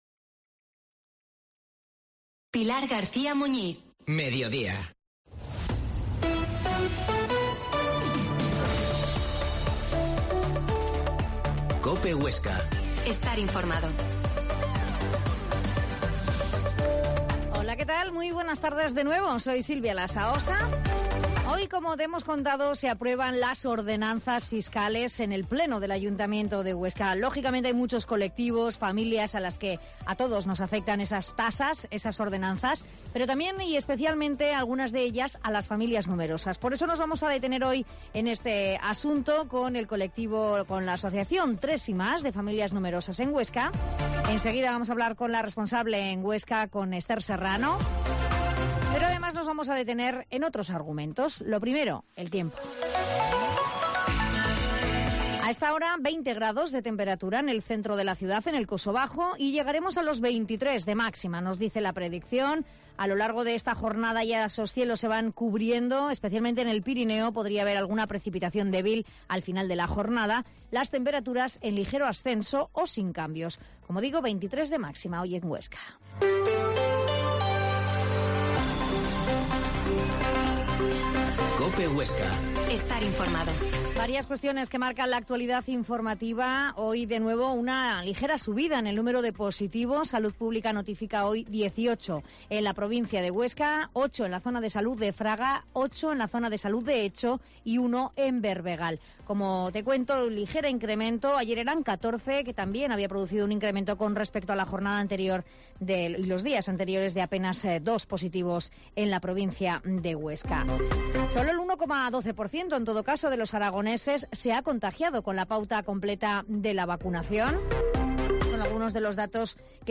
La Mañana en COPE Huesca - Informativo local Mediodía en Cope Huesca 13,50h. Entrevista